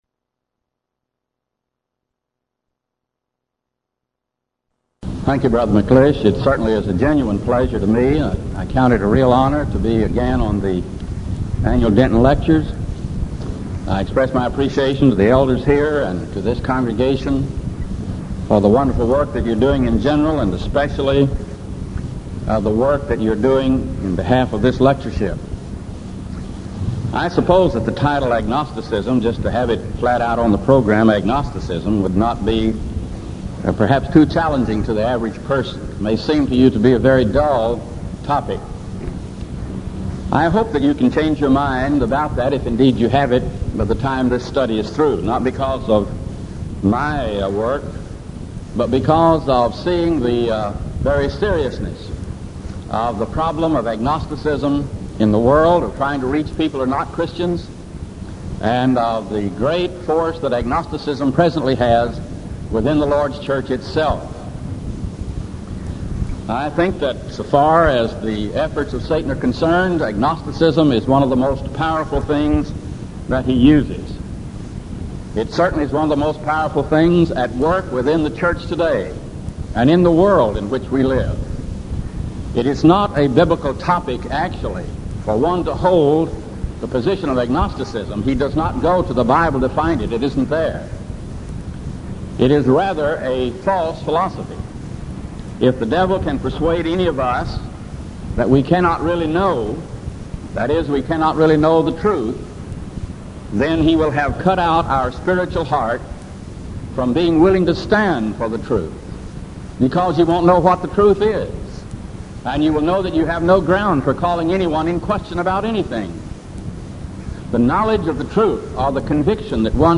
Title: DISCUSSION FORUM: Agnosticism
Event: 1987 Denton Lectures